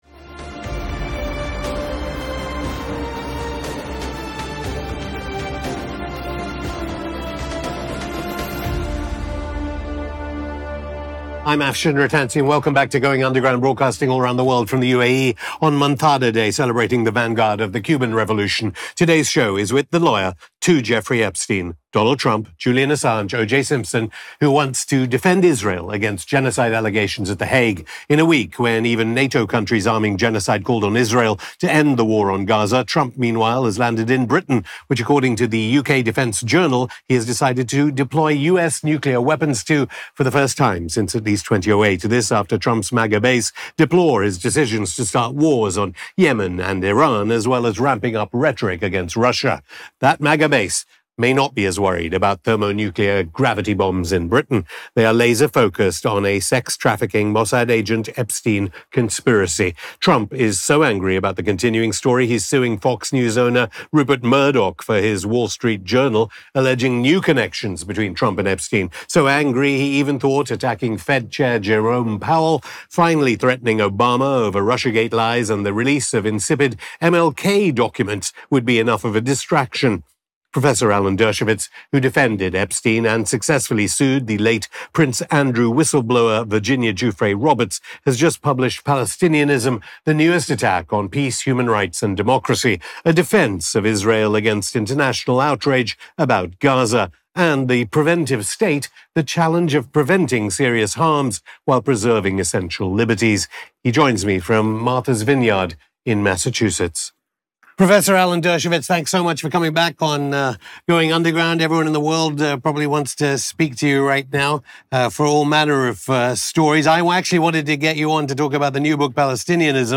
Was Epstein a Mossad Agent? Will Obama go to Prison? (Afshin Rattansi vs Alan Dershowitz) (Afshin Rattansi interviews Alan Dershowitz; 26 Jul 2025) | Padverb